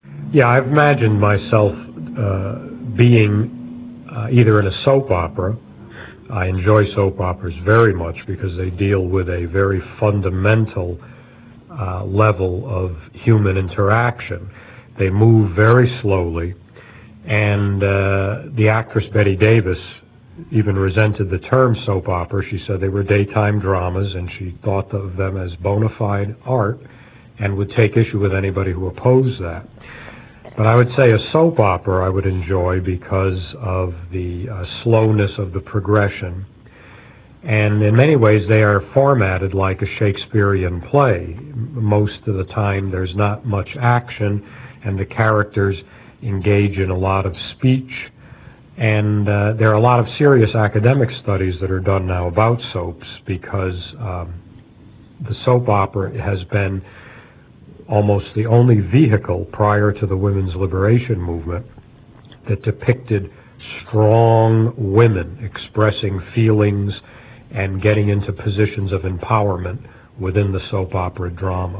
(edited interview)